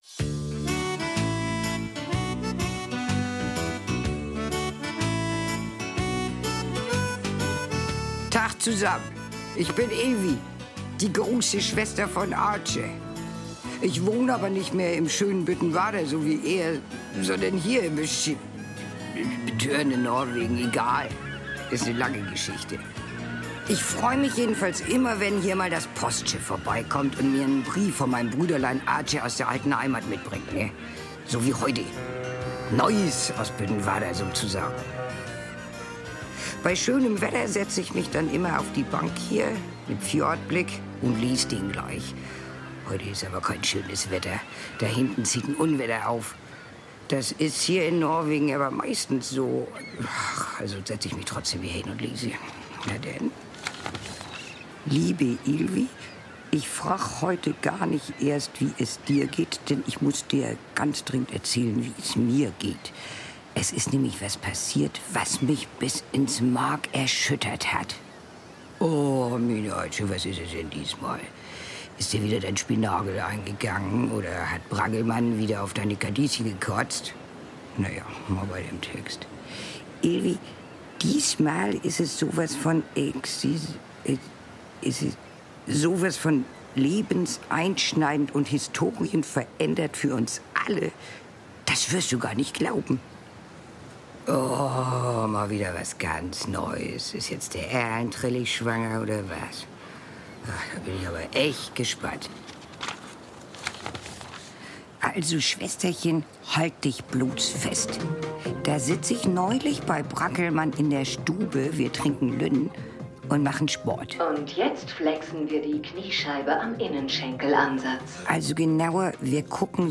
Büttenwarder-Hörspiel: Oh!